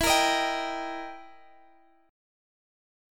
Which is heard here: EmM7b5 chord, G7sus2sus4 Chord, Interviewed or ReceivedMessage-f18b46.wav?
EmM7b5 chord